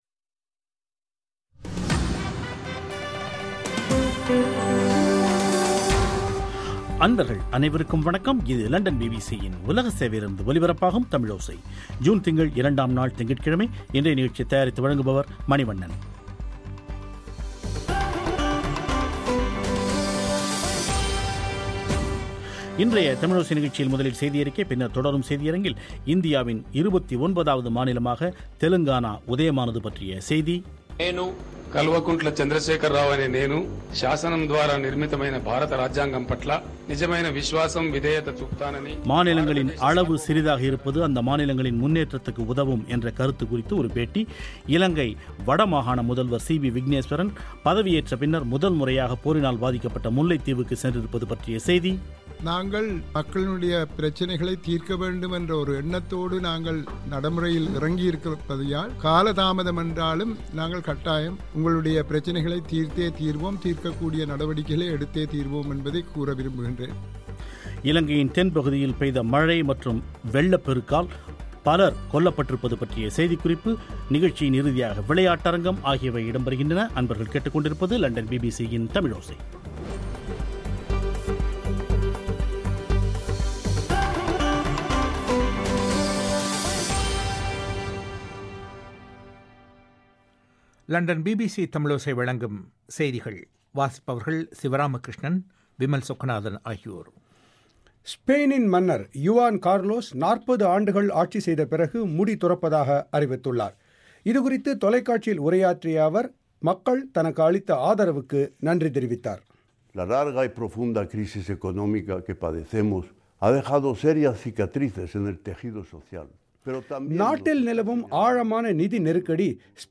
இந்தியாவின் 29வது மாநிலமாக தெலங்கானா உதயமானது பற்றிய செய்தி மாநிலங்களின் அளவு சிறிதாக இருப்பது அம்மாநிலங்களின் முன்னேற்றத்துக்கு உதவும் என்ற கருத்து குறித்து ஒரு பேட்டி